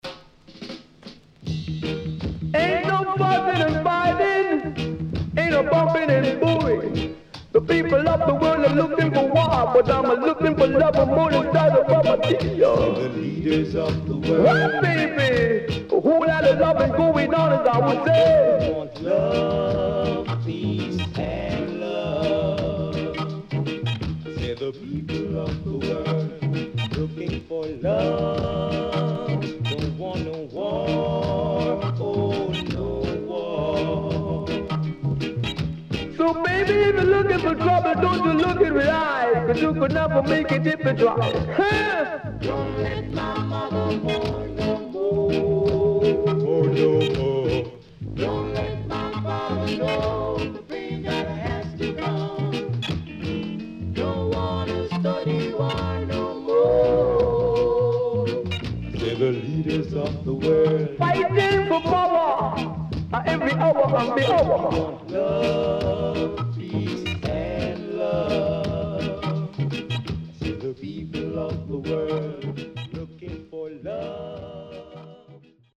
Foundation Rocksteady & Deejay Cut.Good Condition
SIDE A:少しチリノイズ入ります。